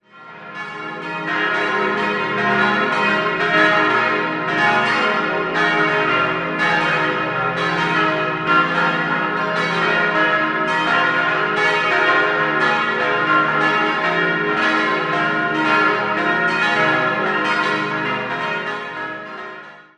Auf einer Anhöhe, umgeben vom Friedhof, befindet sich die im Jahr 1649 errichtete reformierte Kirche. Idealsextett c'-es'-f'-as'-b'-c'' Die Glocken wurden 1958 von der Gießerei Rüetschi in Aarau gegossen.